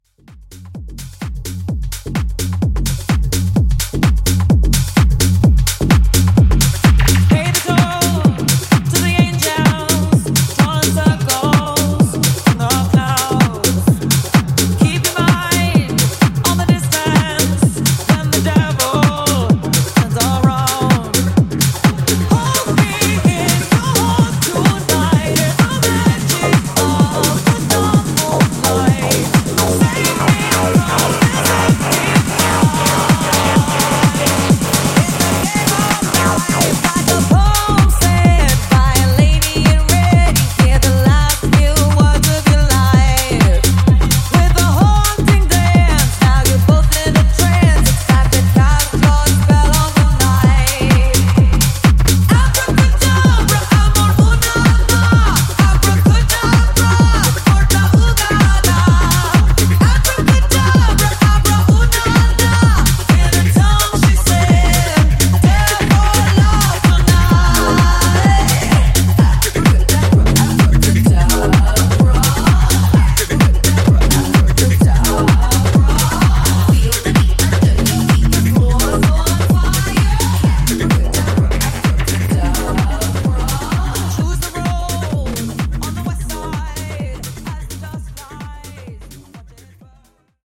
Epic Mashup Edit)Date Added